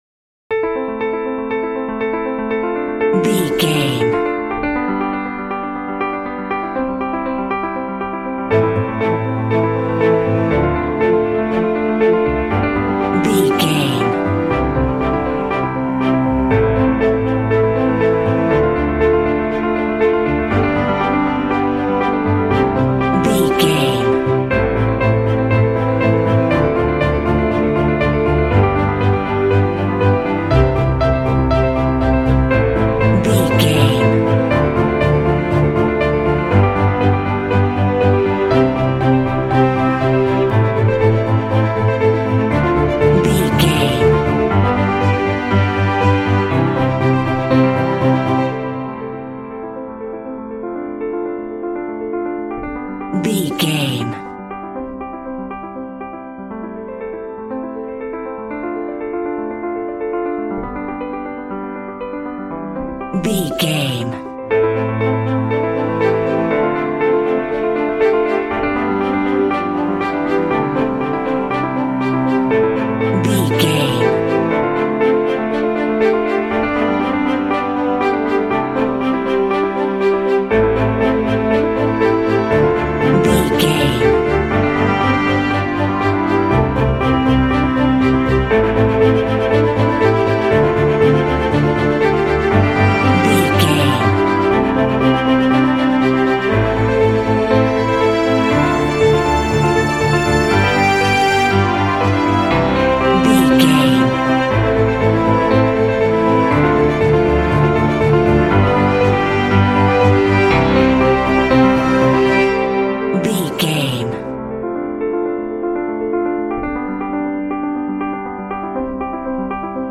Epic / Action
Fast paced
Ionian/Major
Fast
driving
powerful
dreamy
cinematic